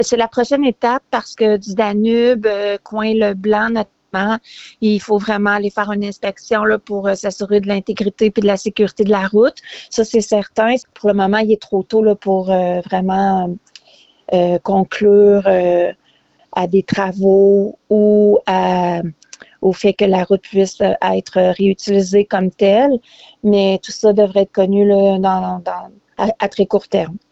En entrevue, la mairesse, Lucie Allard, est revenue sur les nouvelles encourageantes des derniers jours.